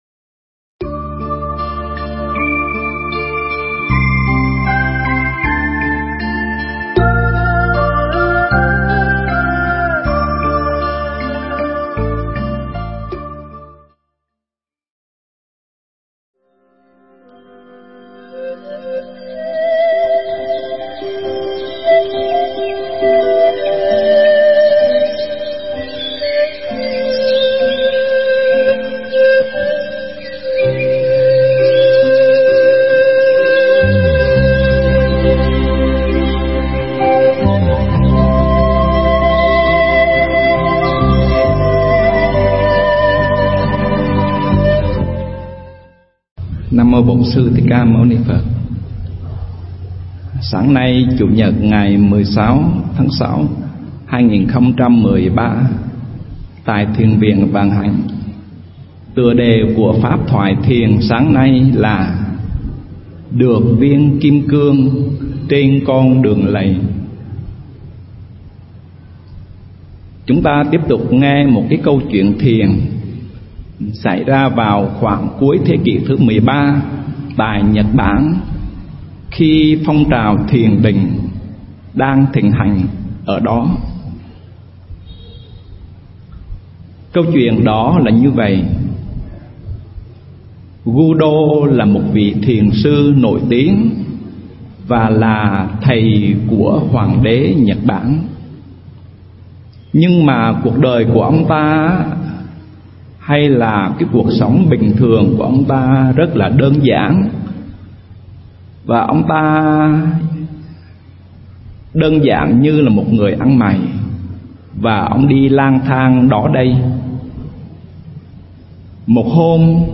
Mp3 thuyết pháp Kim Cương